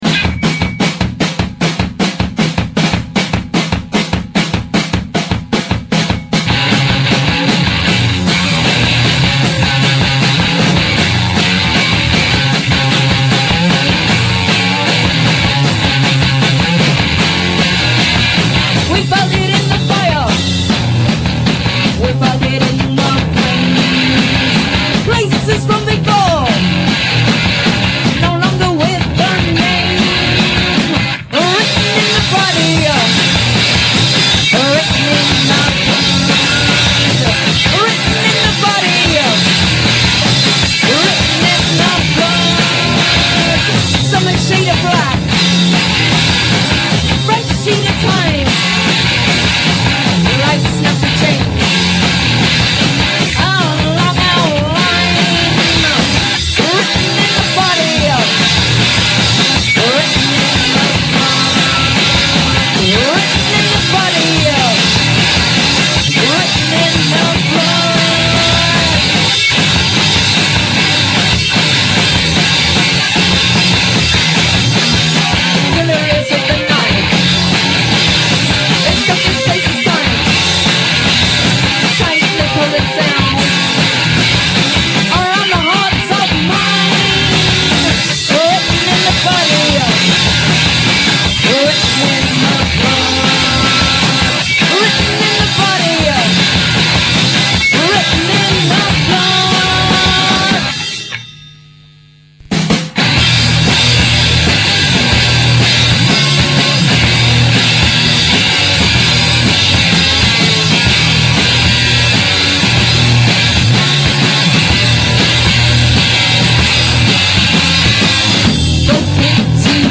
Live Radio Sessions